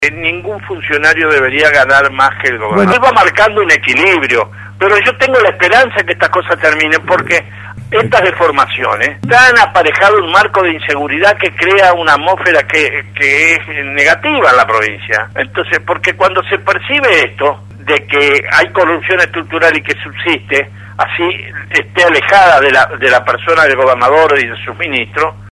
En comunicación con RADIO RD 99.1, el ex senador Héctor Maya, se refirió a la actualidad tanto nacional como provincial.